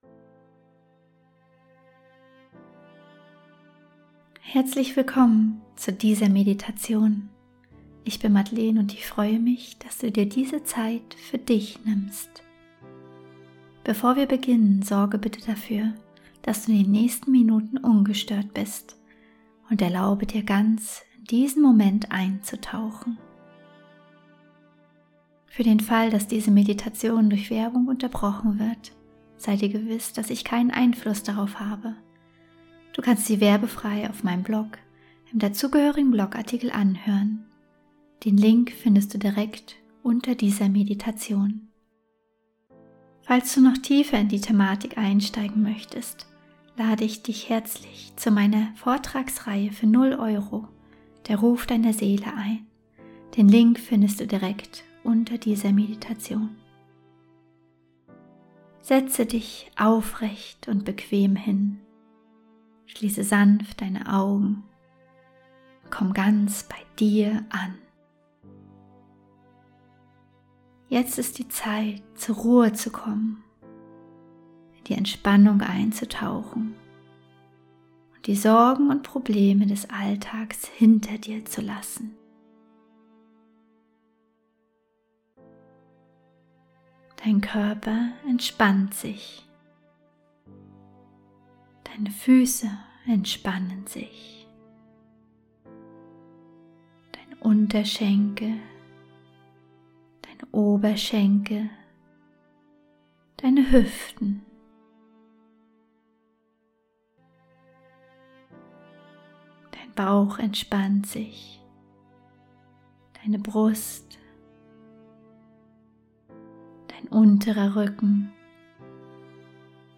12-Minuten geführte Meditation: Von Ersatzbefriedigung zu wahrer Erfüllung ~ Heimwärts - Meditationen vom Funktionieren zum Leben Podcast
Meditation_wahre_Erfuellung.mp3